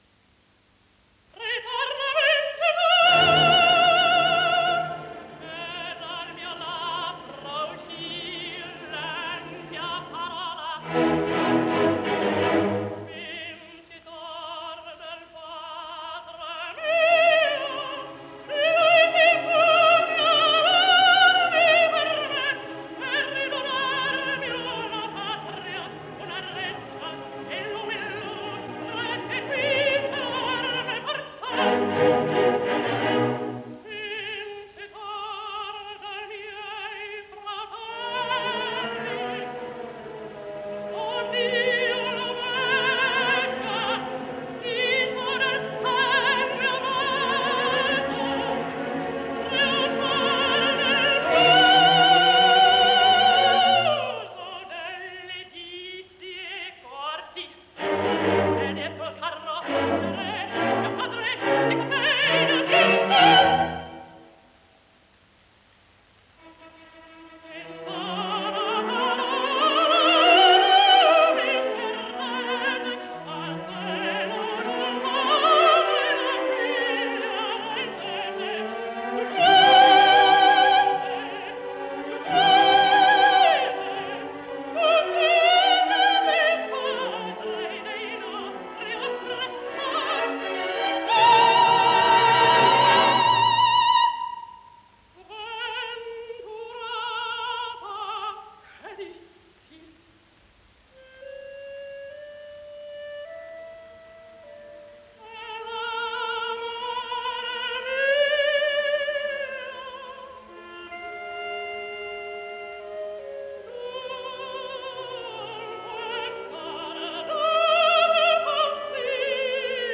il secondo brano dall’opera Aida “Ritorna vincitor” incisione elettrica del 17 luglio 1928.